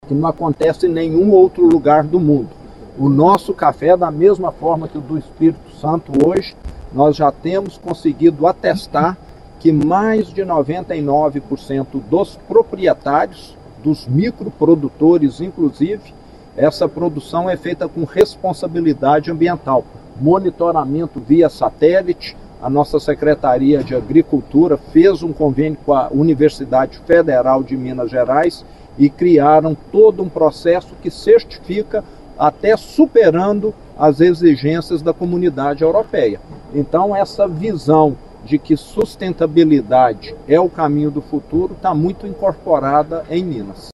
O governador de Minas Gerais, Romeu Zema, ressaltou os avanços do estado na transição energética e na exportação de produtos com baixa pegada de carbono, como o “aço mais verde do mundo”, produzido com carvão vegetal.